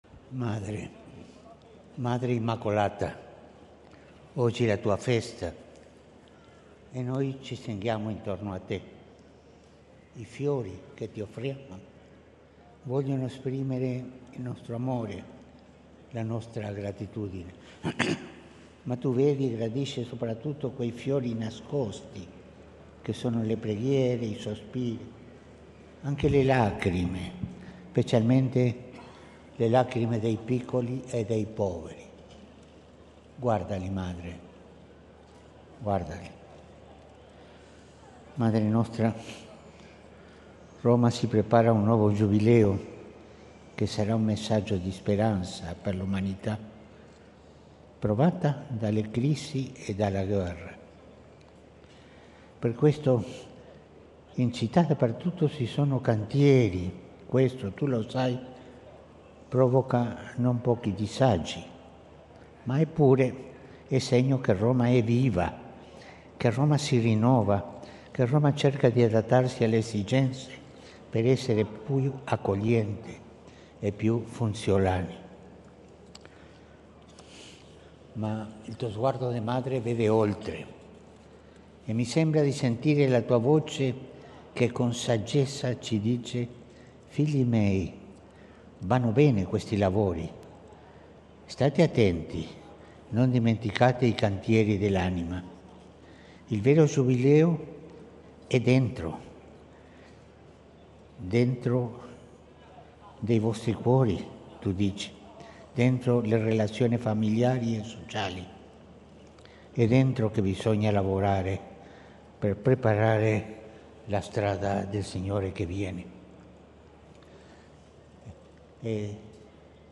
Atto di venerazione di papa Francesco all'Immacolata in Piazza di Spagna (8 dicembre 2024).